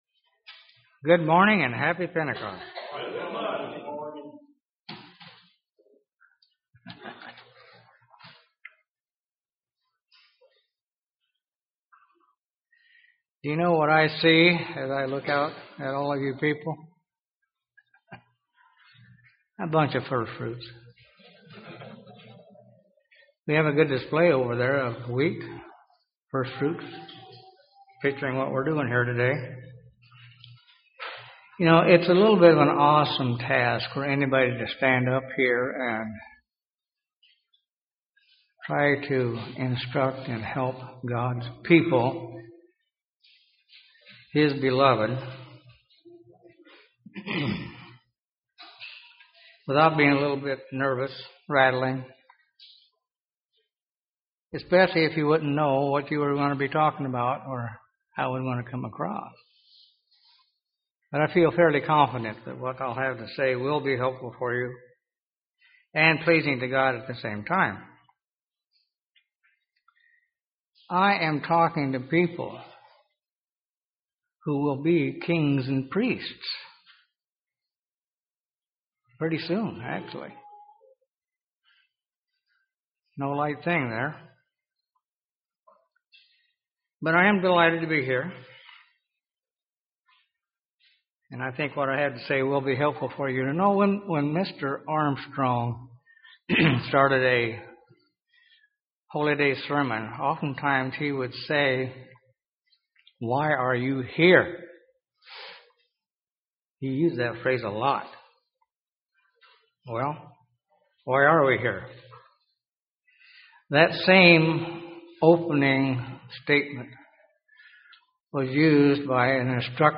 Sermons
Given in Birmingham, AL Gadsden, AL Huntsville, AL